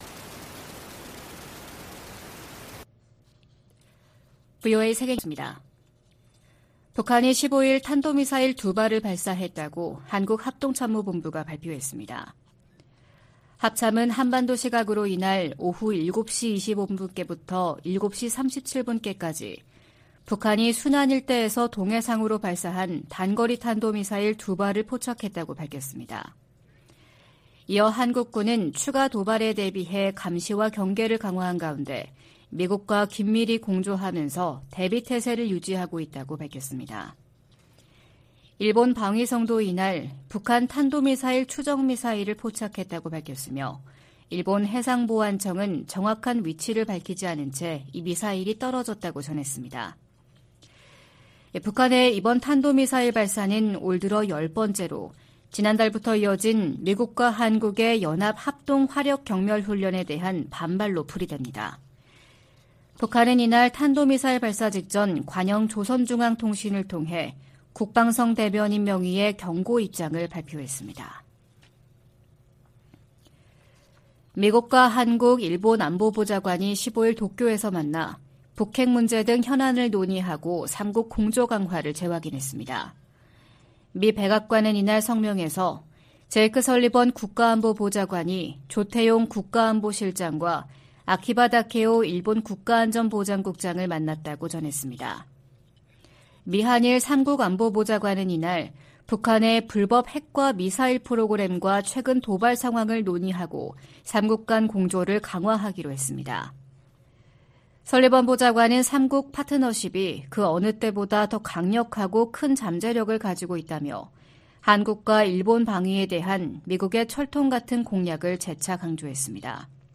VOA 한국어 '출발 뉴스 쇼', 2023년 6월 16일 방송입니다. 미국은 심해지는 중국과의 경쟁을 관리하기 위해 한국, 일본 등과 동맹을 강화하고 있다고 미국 정부 고위 관리들이 밝혔습니다. 미국 하원 세출위원회 국방 소위원회는 2024회계연도 예산안에서 미군 유해 수습과 신원 확인 외에는 어떤 대북 관련 지원도 할수 없도록 했습니다.